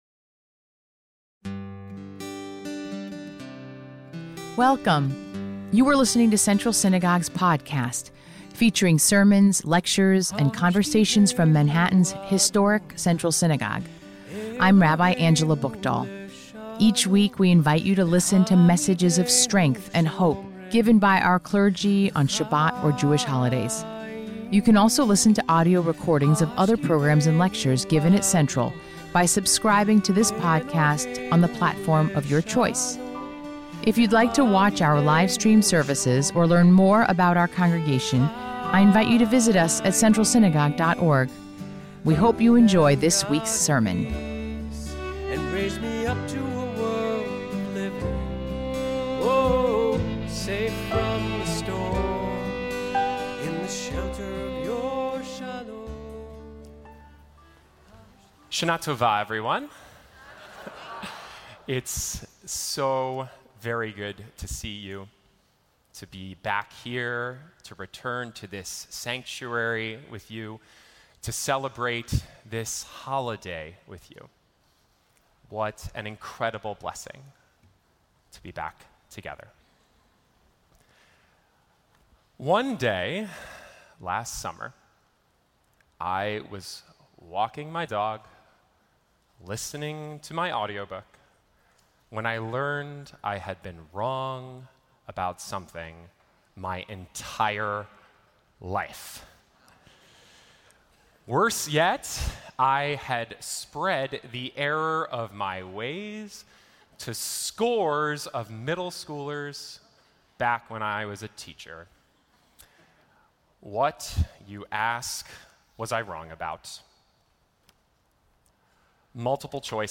Weekly Meditation led by Rabbi Angela BuchdahlApril 30, 2024